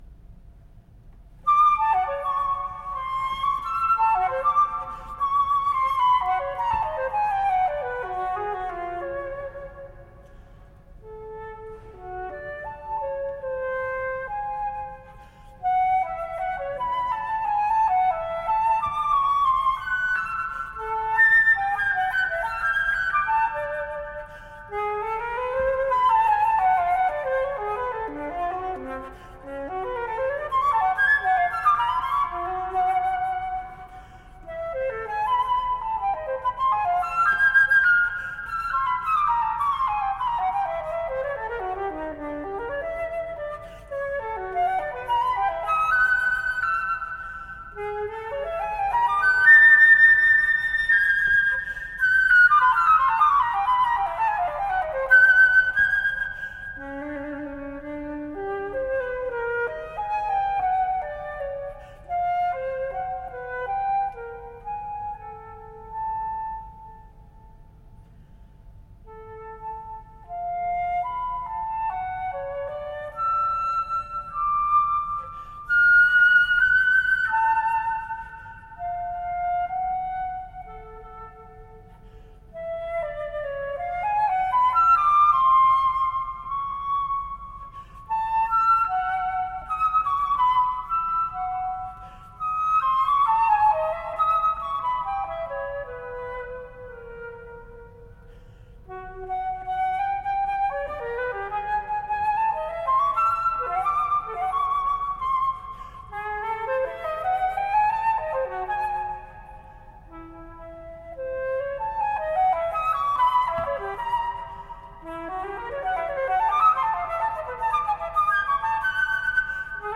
flutiste